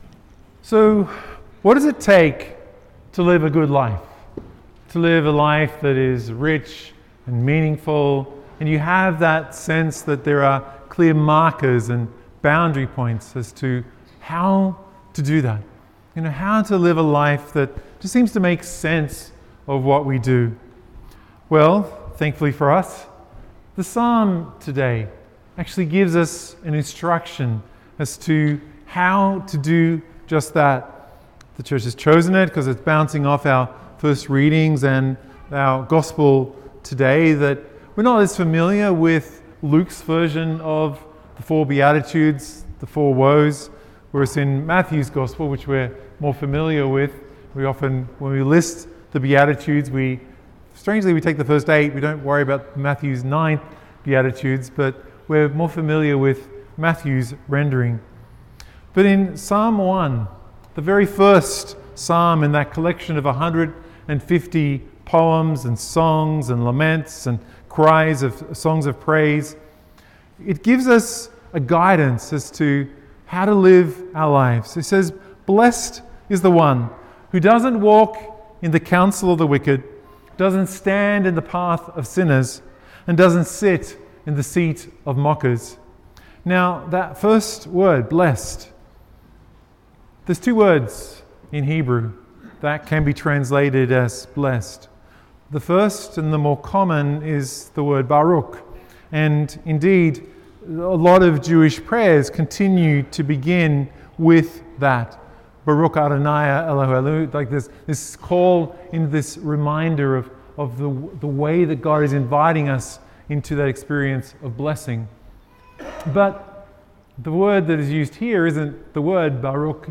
Liturgy of the Word 06C – Ordinary Sunday 6